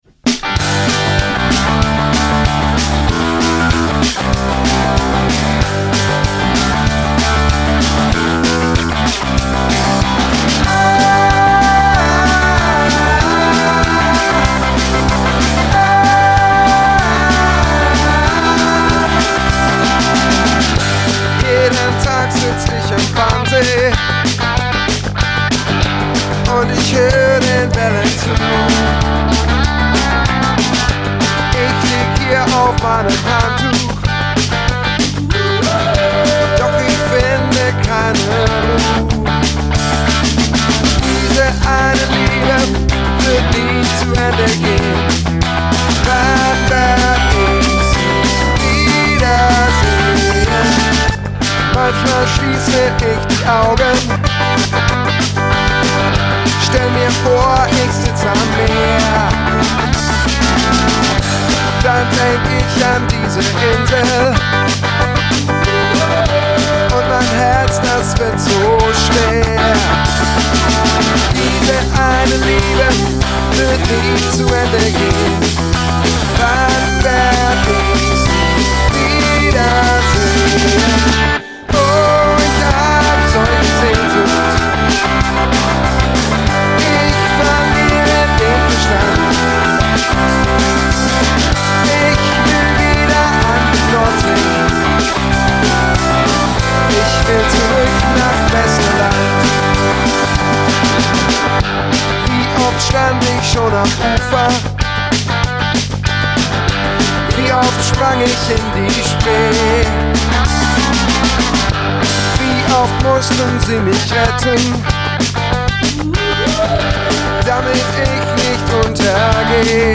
100 % Live Musik!